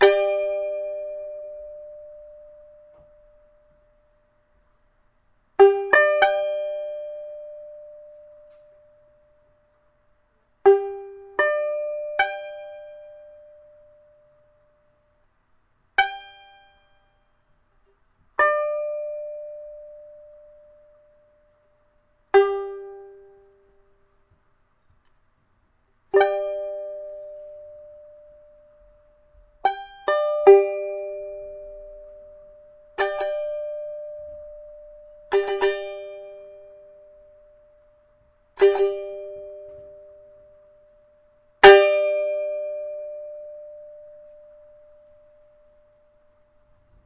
弦乐棒 " 弦乐棒7音阶条琴
描述：弦乐弹拨用Blue Snowball麦克风录制，16bit
Tag: 俯仰 乱弹 strumstick 乐器 吉他 乱弹-stick 拔毛 笔记 样品